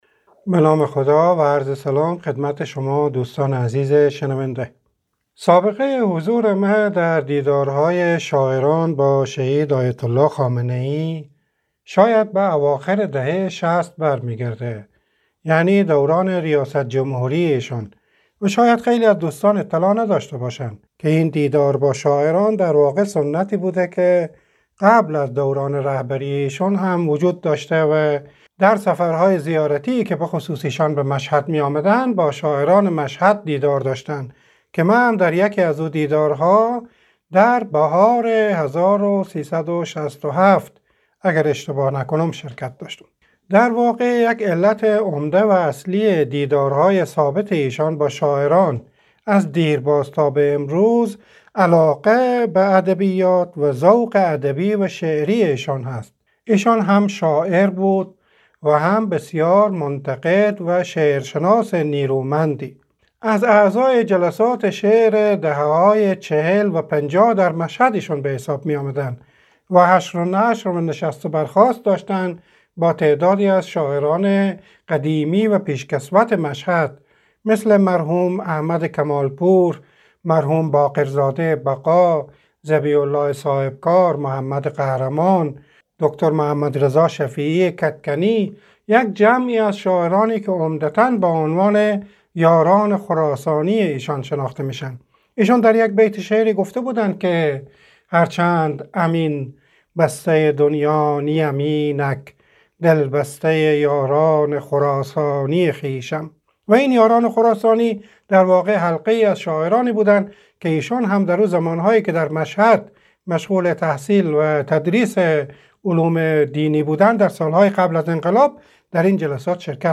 محمدکاظم کاظمی در گفت و گو با رادیو دری افزود